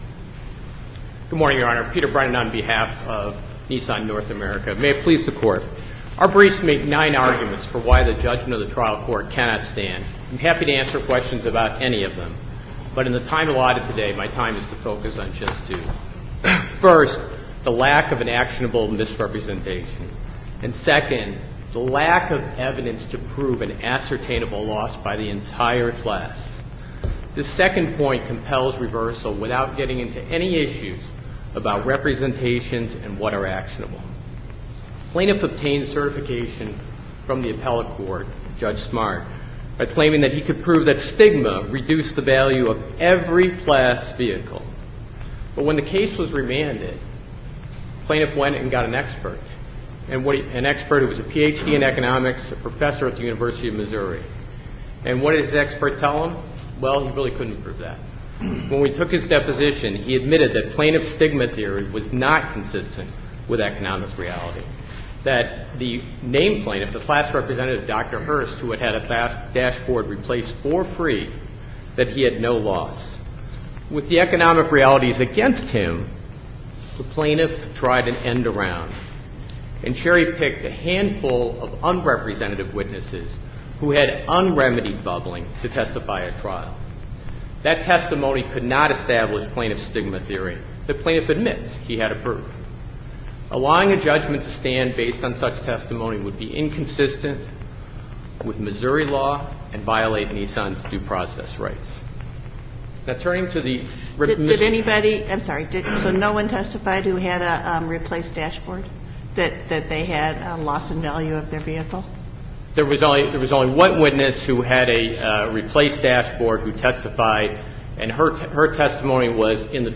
MP3 audio file of oral arguments in SC95538